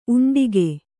♪ uṇḍige